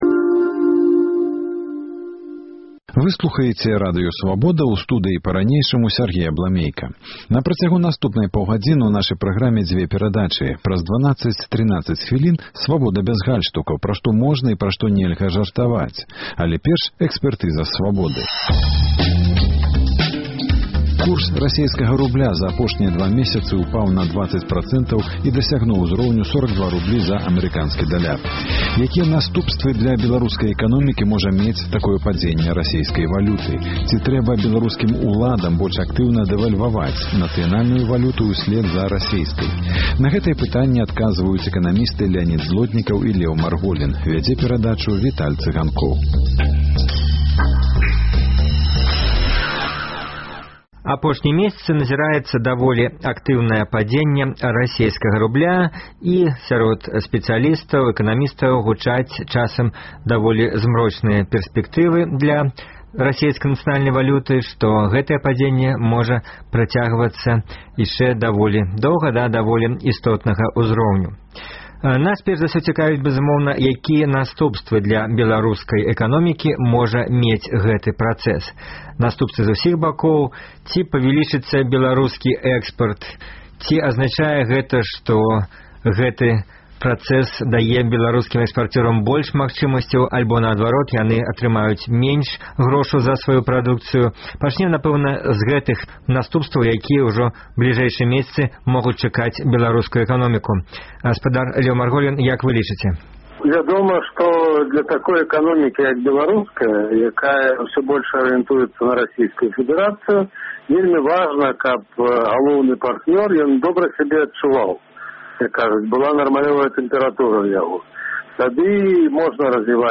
Курс расейскага рубля за апошнія два месяцы ўпаў на 20 працэнтаў і дасягнуў узроўню 43 рублёў за амэрыканскі даляр. Якія наступствы для беларускай эканомікі можа мець падзеньне расейскага рубля? Ці трэба беларускім уладам больш актыўна дэвальваваць нацыянальную валюту ўсьлед за расейскай? На гэтыя пытаньні ў перадачы Экспэртыза Свабоды адказваюць эканамісты